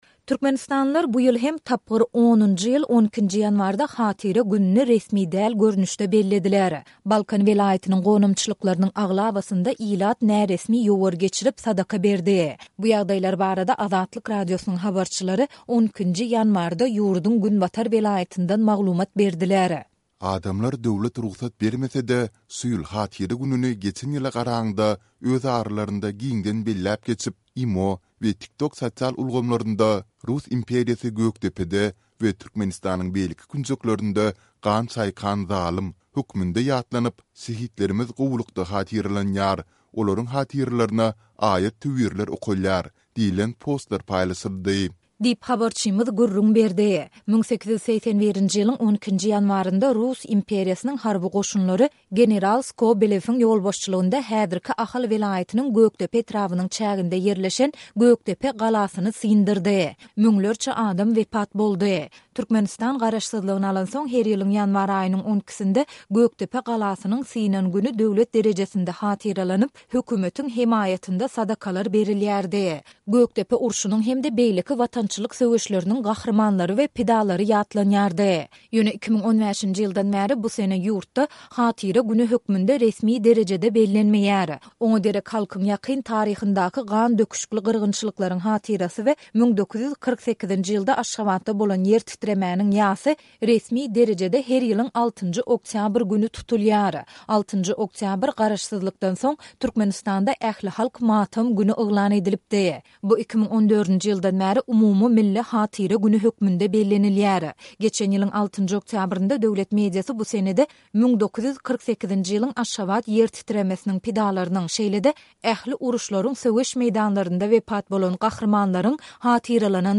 Türkmenistanlylar bu ýyl hem, tapgyr 10-njy ýyl, 12-nji ýanwarda Hatyra gününi resmi däl görnüşde ýatladylar. Balkan welaýatynyň gonamçylyklarynyň aglabasynda ilat özbaşdak ýowar geçirip, sadaka berdi. Bu ýagdaýlar barada Azatlyk Radiosynyň habarçylary 12-nji ýanwarda sebitden maglumat berdiler.